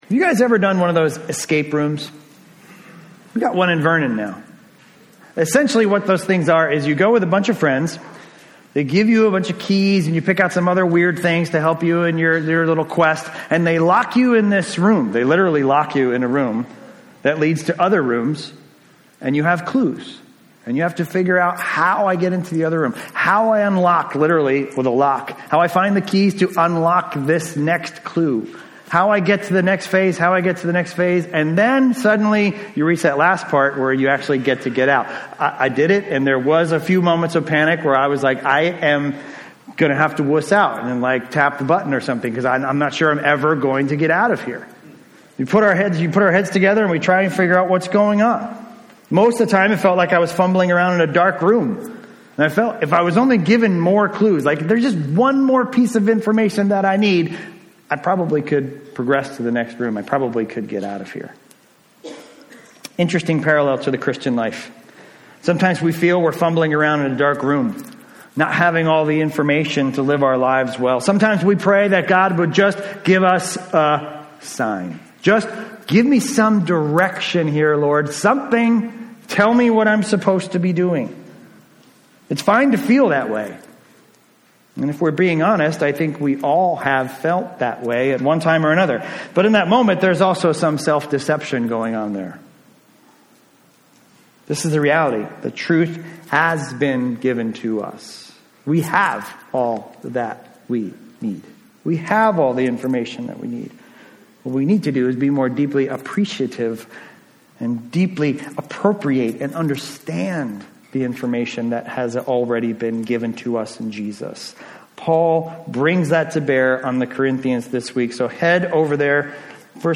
A message from the series "1 Corinthians." In 1 Corinthians 2:1-5 we learn that we must be resolved to let our faith rest in the power of God in the gospel.